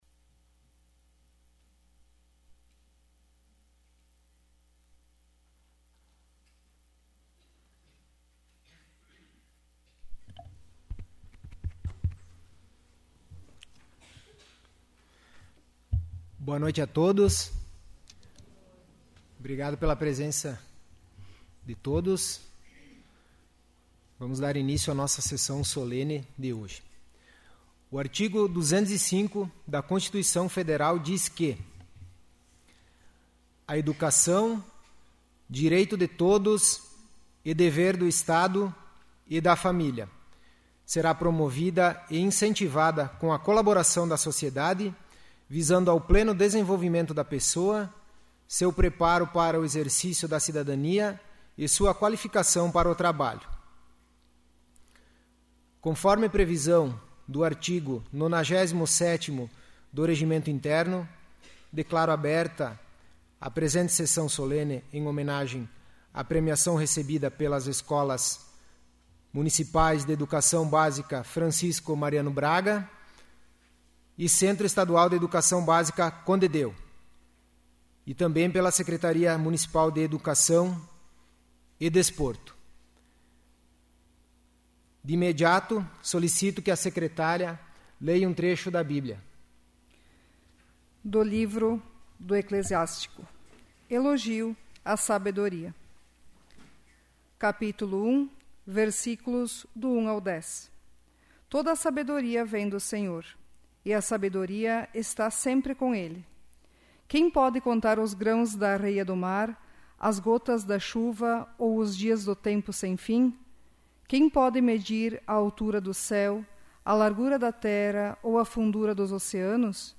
'áudio da sessão do dia 26/03/2025'